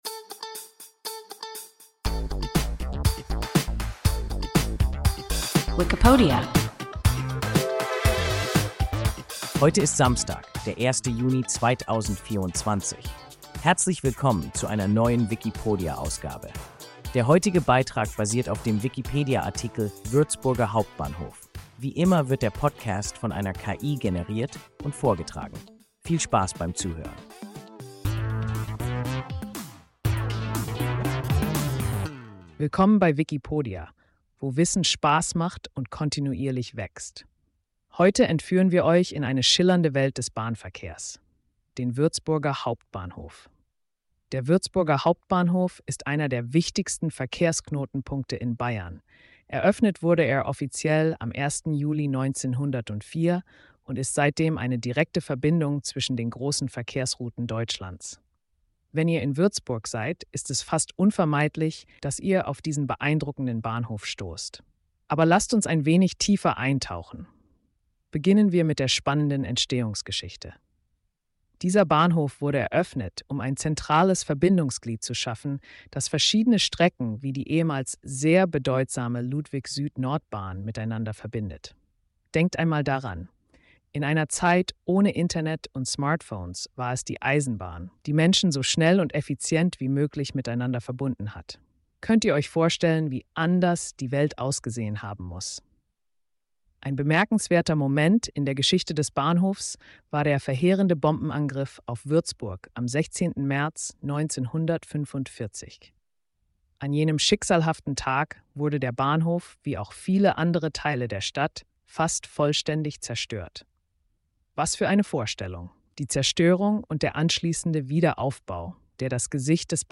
Würzburger Hauptbahnhof – WIKIPODIA – ein KI Podcast